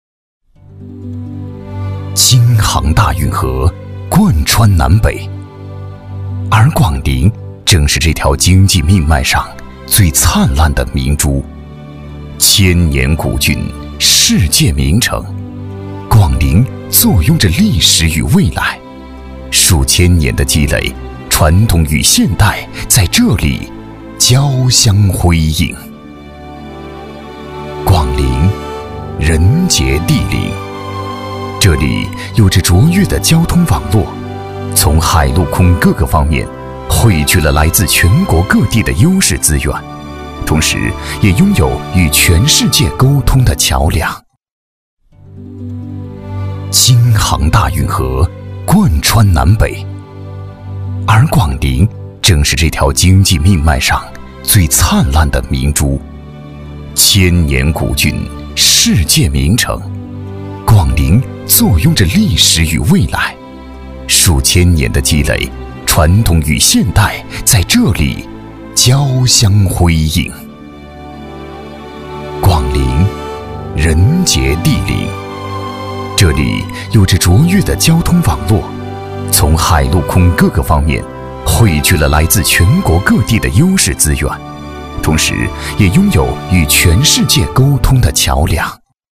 • 男S337 国语 男声 专题片-广陵-厚重、大气 大气浑厚磁性|沉稳